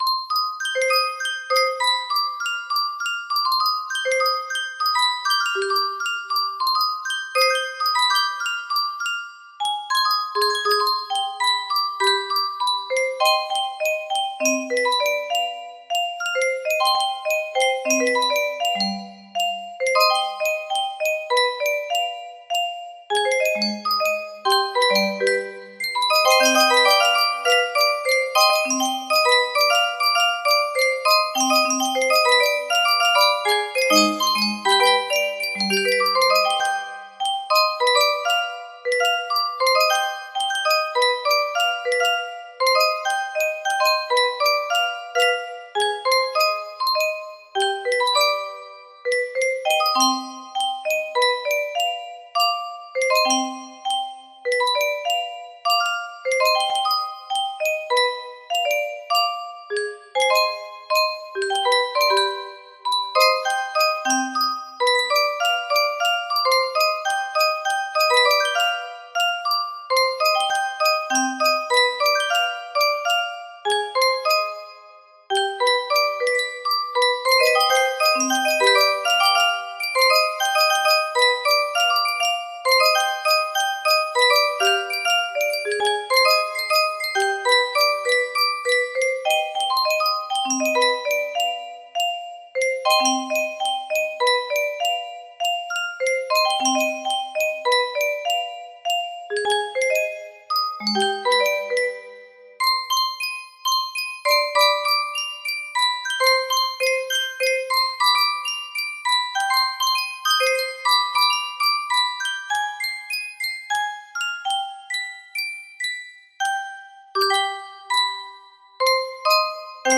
Threads Of Gold 8 music box melody
Full range 60